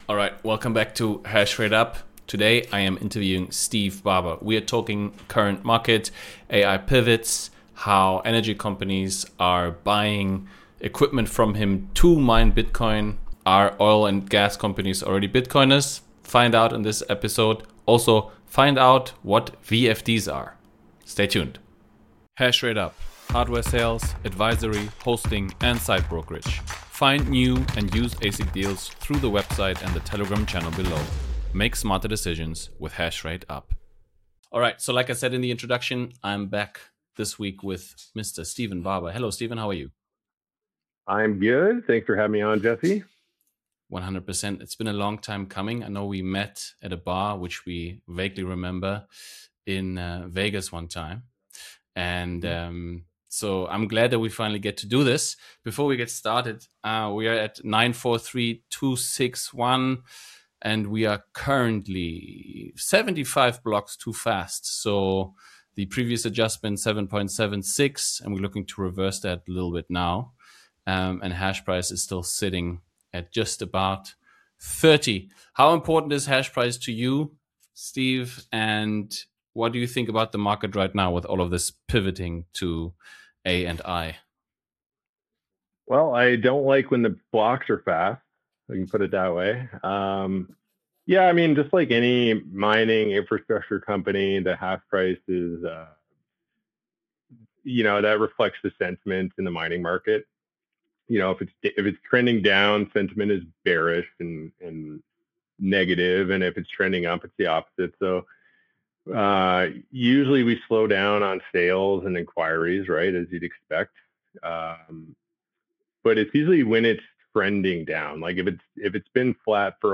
Join us as we talk with industry experts, researchers, and thought leaders to understand how Bitcoin is shaping the future of power and investigate the energy challenges and opportunities Bitcoin and its ecosystem bring to the t